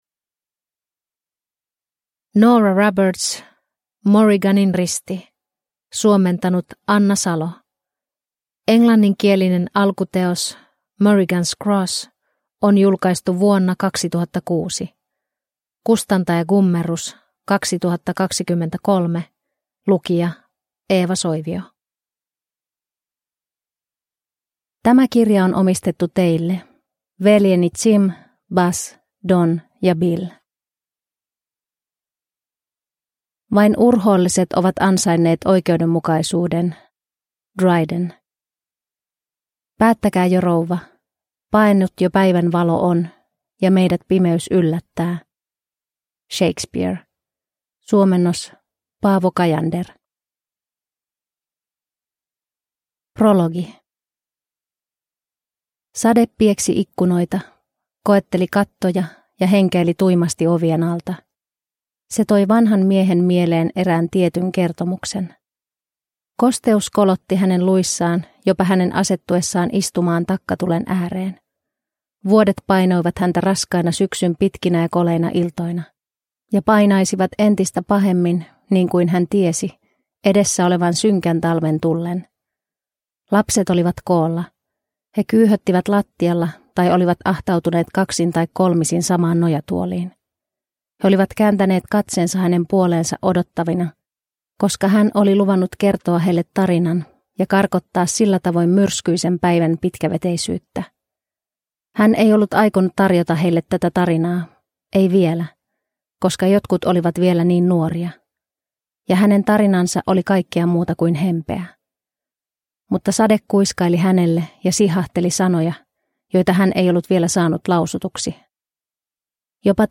Morriganin risti – Ljudbok – Laddas ner